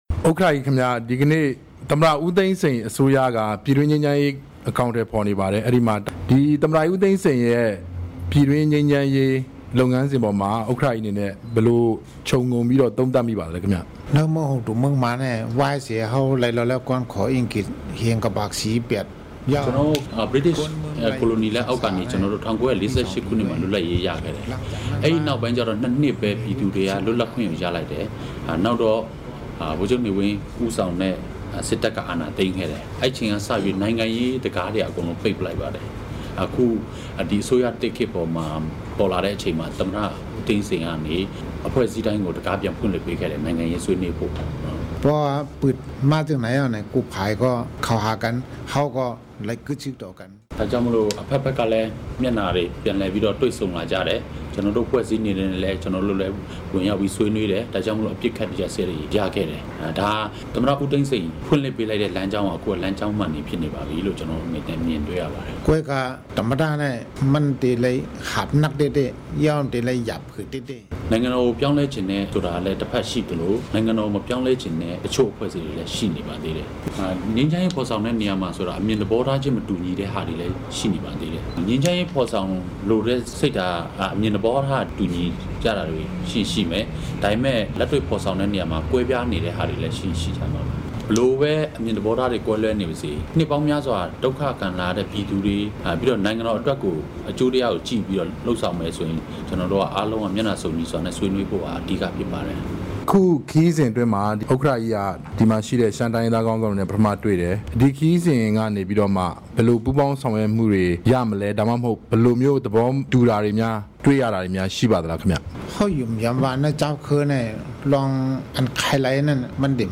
ဒုတိယ ဗိုလ်ချုပ်ကြီး ယွက်စစ်က စကားပြန်ကတဆင့် ပြန်လည်ဖြေကြားခဲ့တာပါ။